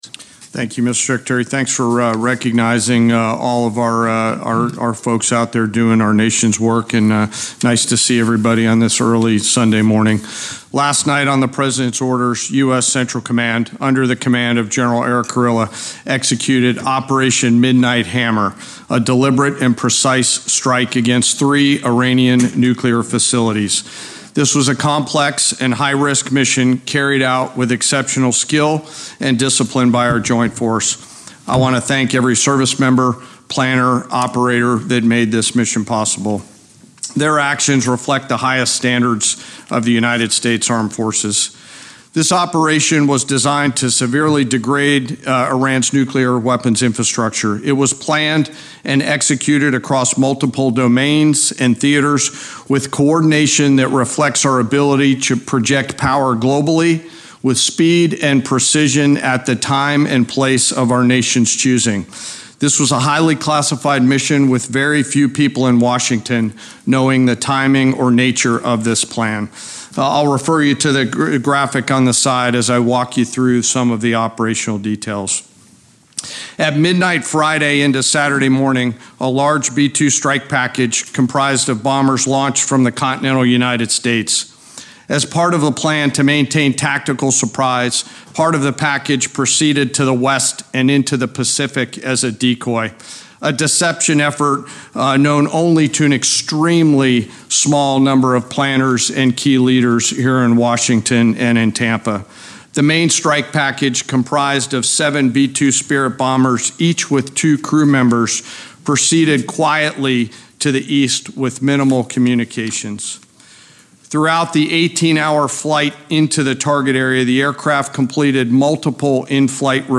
Press Briefing on Operation Midnight Hammer
delivered 22 June 2025, The Pentaqgon